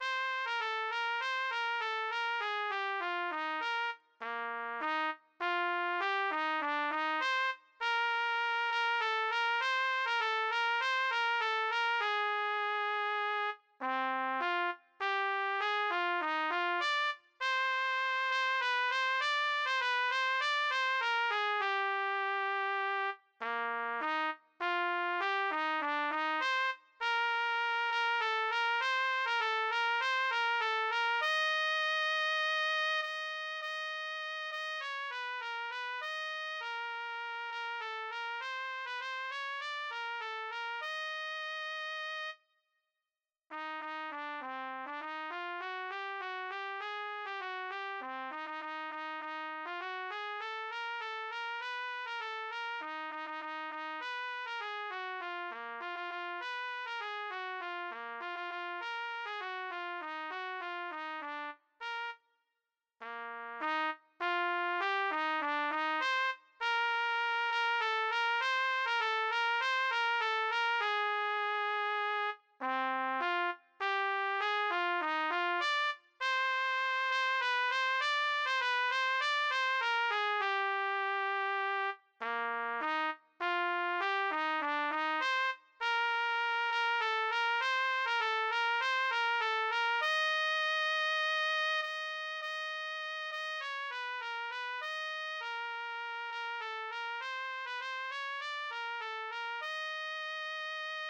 A=Melody-for more experienced players